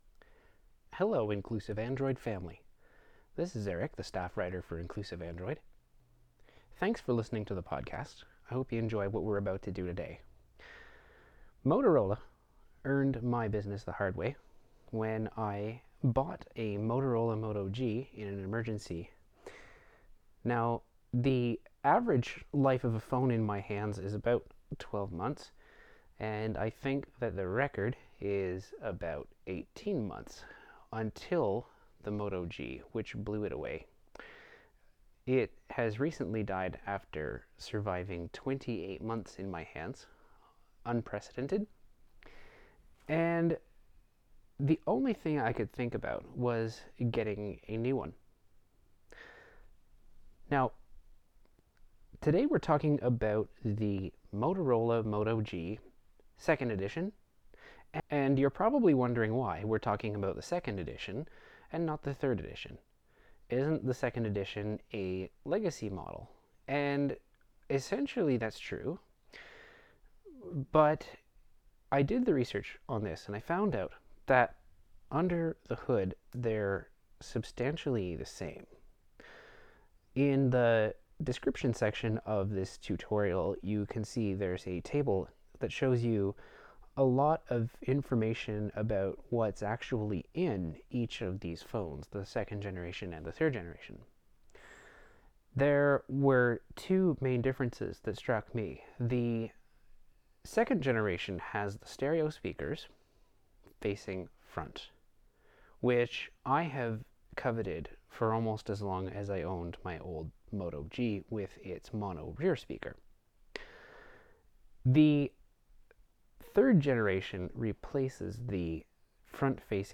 In this review we unbox the moto G 2nd edition, insert a sim card, and get the phone up and running with talkback. For this review, I'm using a set of Binoral field mics which I usually use for taping live rock shows. I'm hoping to give you a great sample of the speaker system.